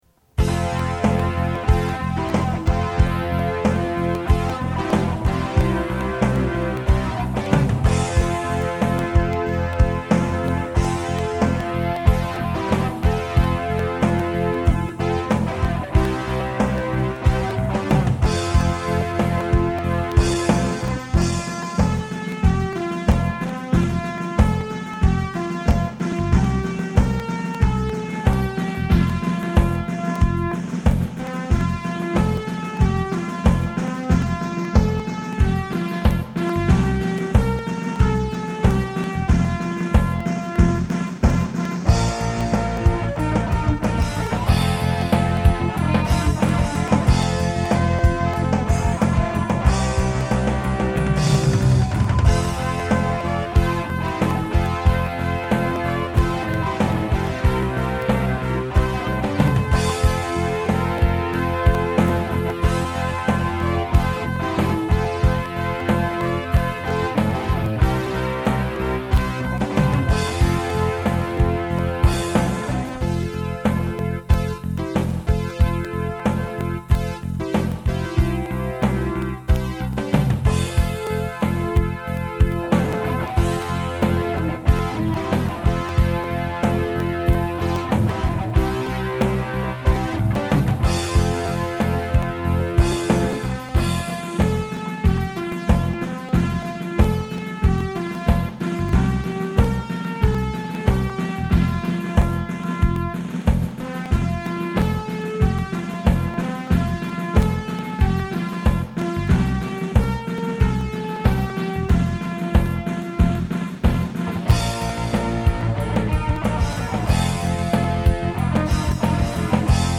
"Rockversion" hier zum Downloaden